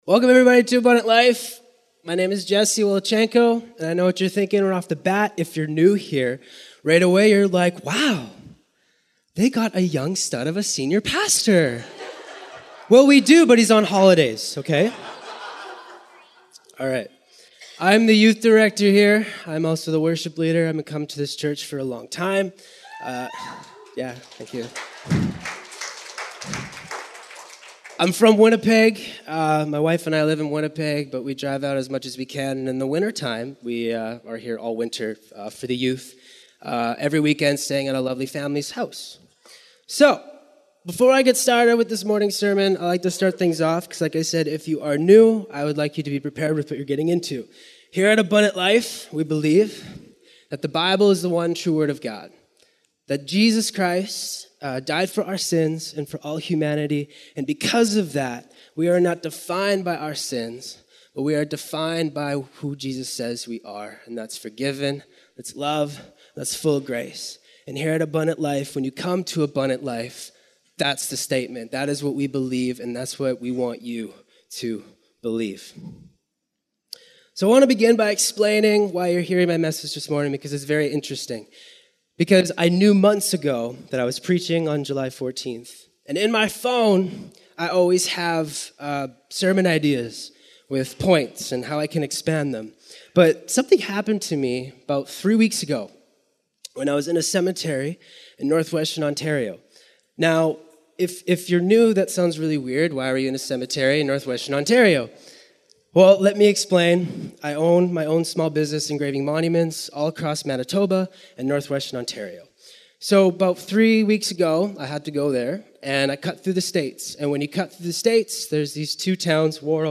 Sermons | Abundant Life Chapel